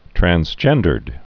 (trăns-jĕndərd, trănz-)